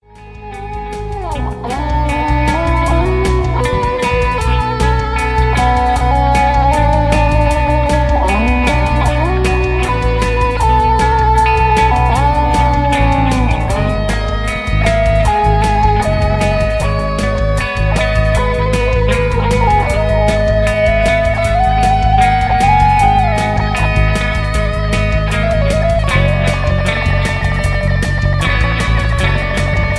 Electric Guitar instrumental single track. Home recording.
All guitars and bass